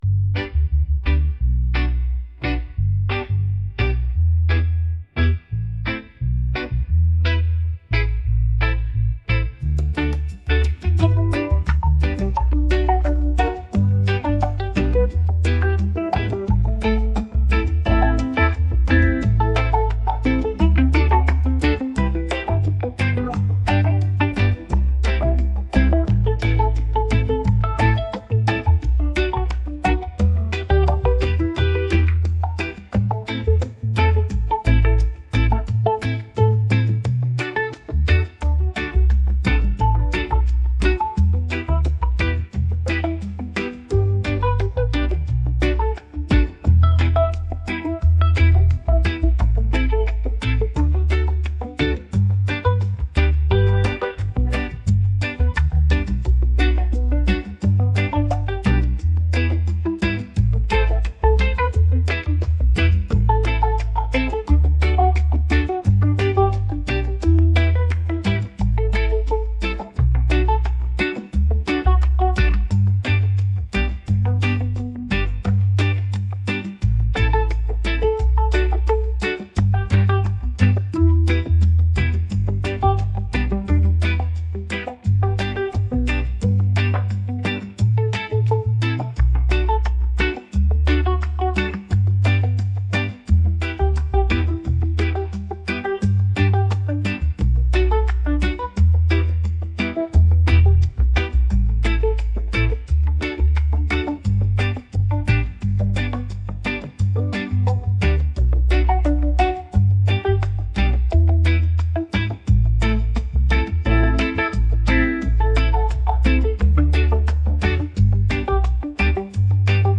acoustic | reggae